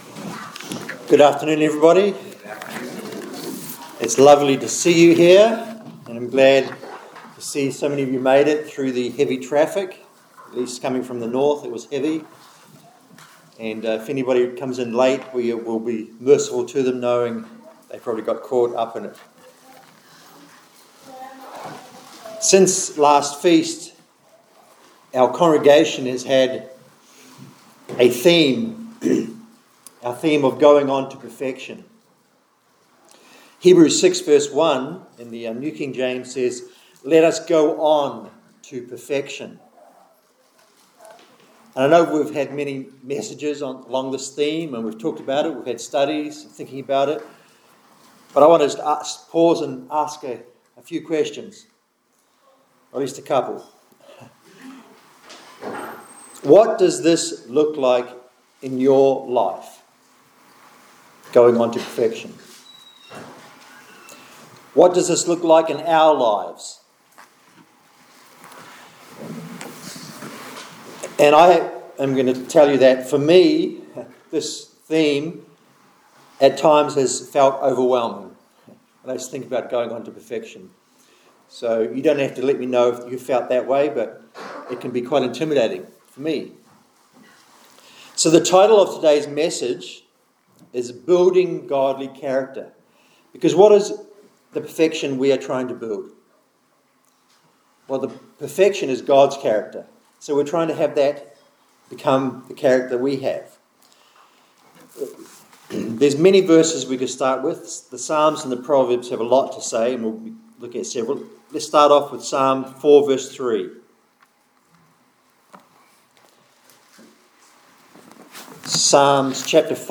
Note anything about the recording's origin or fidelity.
Given in Olympia, WA Tacoma, WA